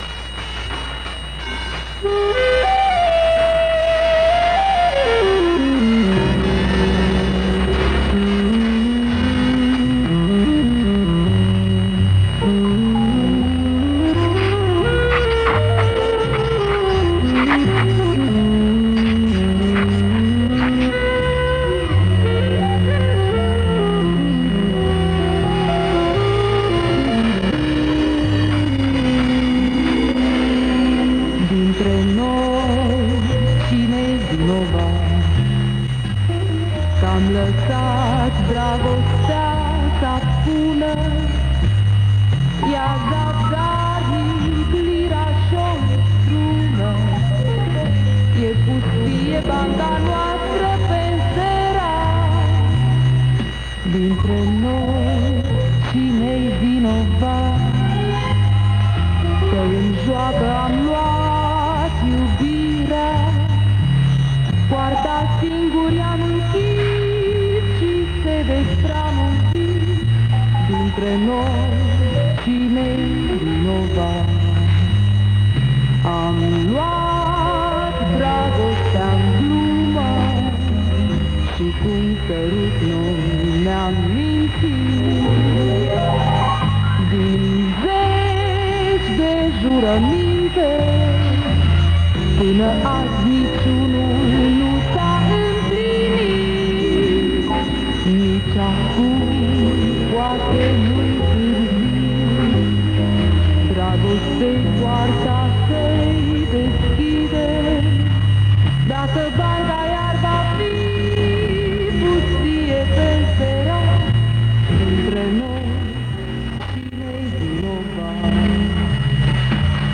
Несколько старых записей с приёмника. Качество плоховатое.